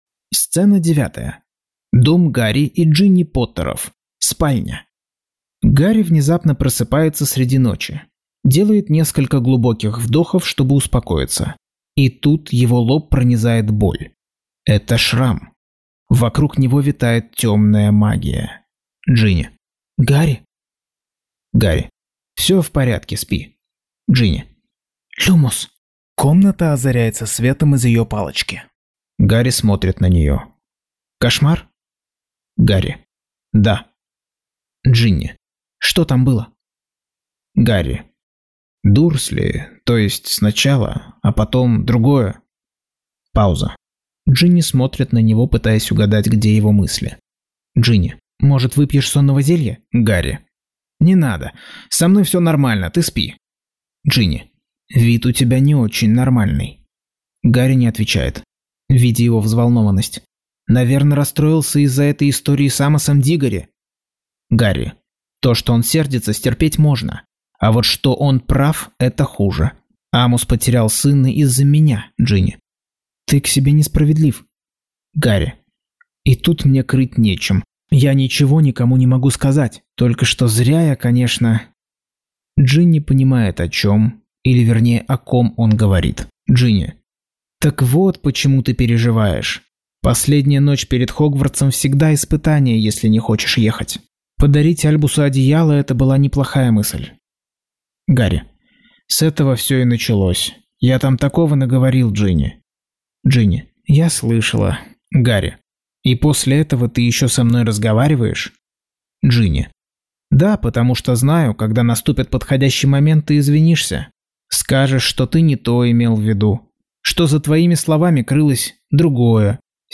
Аудиокнига Гарри Поттер и проклятое дитя. Часть 6.